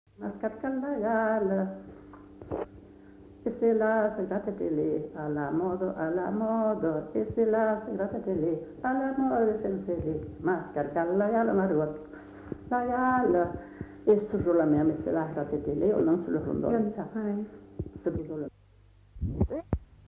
[Brocas. Groupe folklorique] (interprète)
Lieu : [sans lieu] ; Landes
Genre : chant
Type de voix : voix de femme
Production du son : chanté
Danse : rondeau